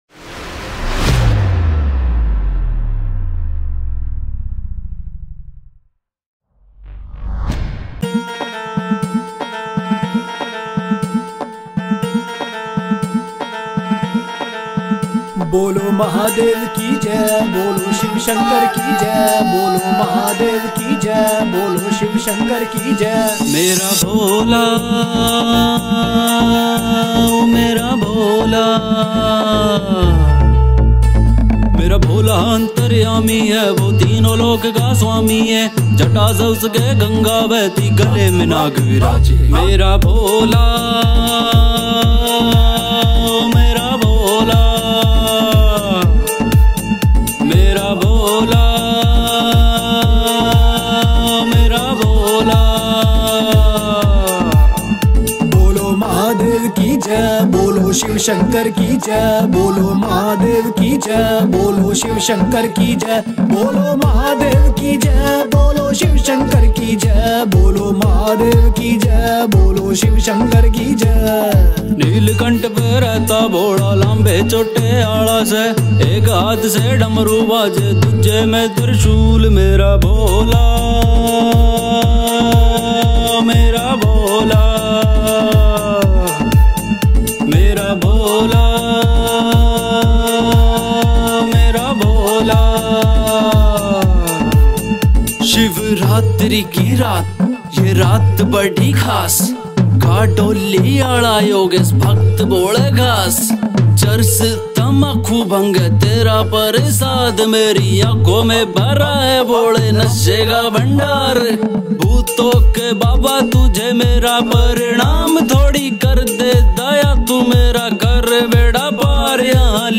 Shivji Bhajan